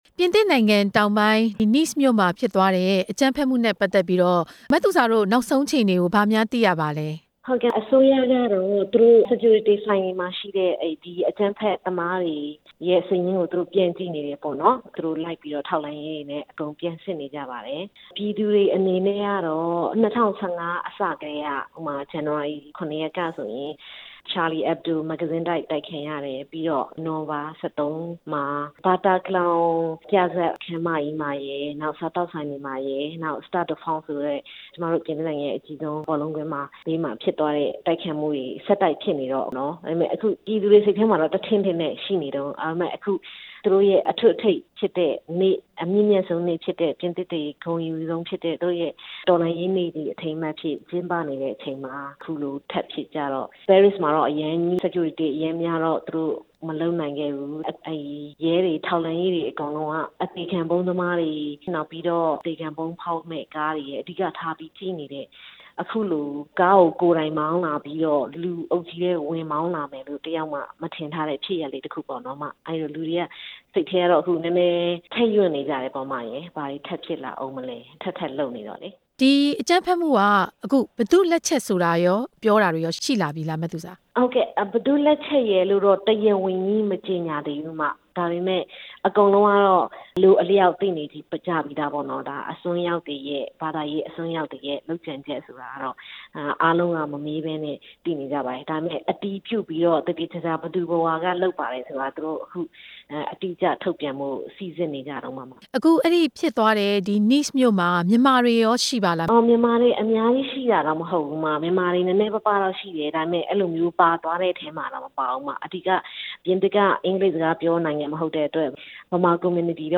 ပြင်သစ်မှာ အကြမ်းဖက် တိုက်ခိုက်ခံရမှုနောက်ဆုံးအခြေအနေ ဆက်သွယ်တင်ပြချက်